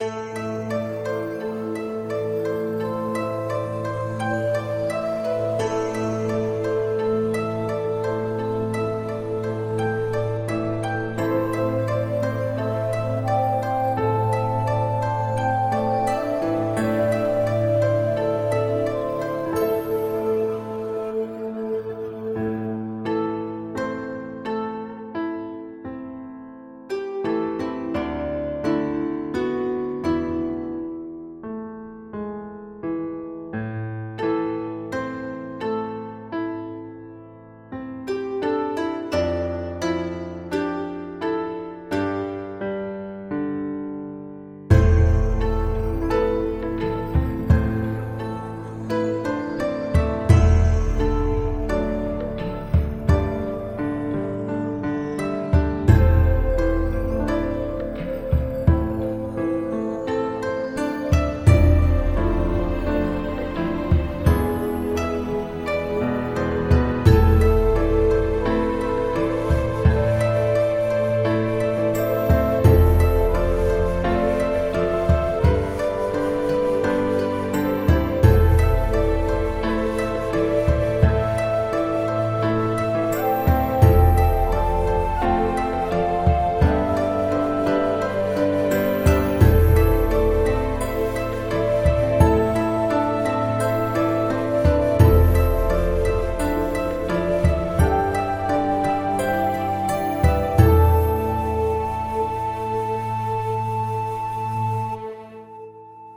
旧版港口昼间bgm